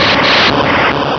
Cri d'Électrode dans Pokémon Rubis et Saphir.